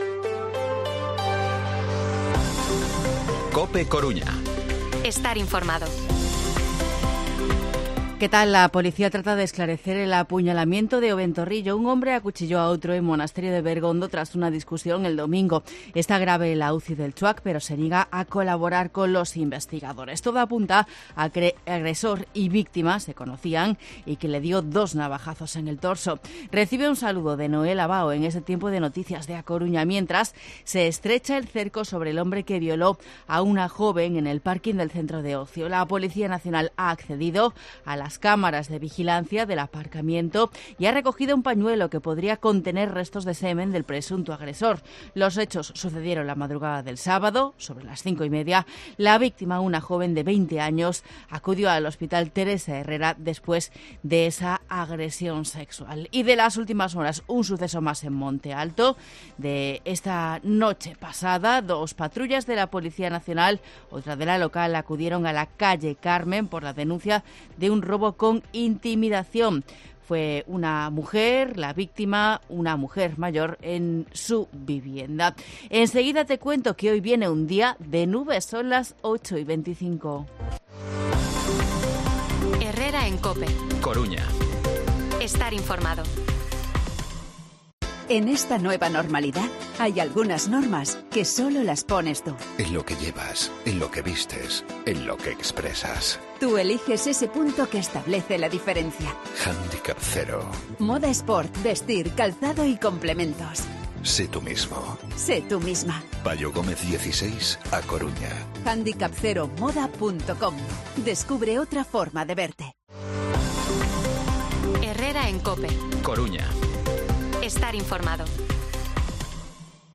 Informativo Herrera en COPE en A Coruña martes, 23 de mayo de 2023 8:24-8:29